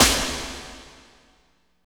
53.06 SNR.wav